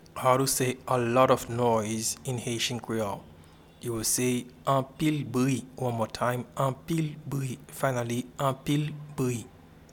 Pronunciation and Transcript:
A-lot-of-noise-in-Haitian-Creole-Anpil-bri.mp3